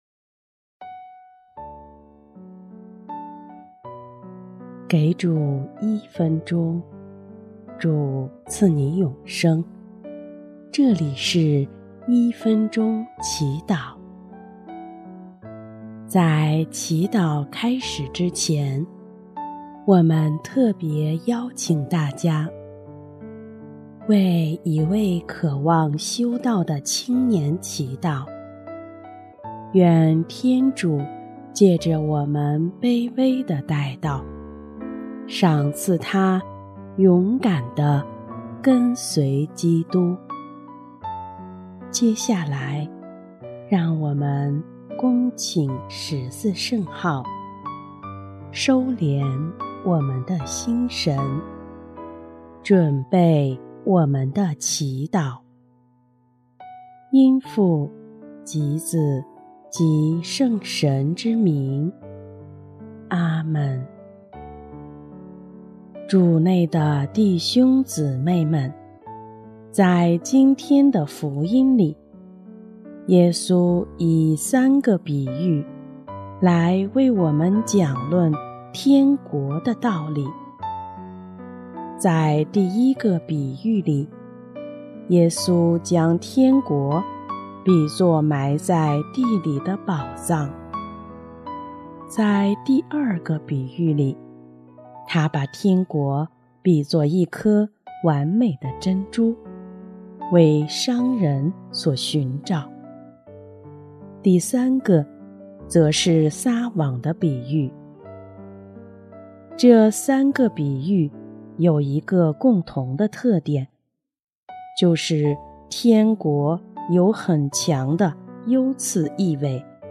【一分钟祈祷】| 7月30日 辨别优先次序，全心向往天国
音乐：第三届华语圣歌大赛参赛歌曲《我的圣召就是爱》（为一位渴望修道的青年祈祷）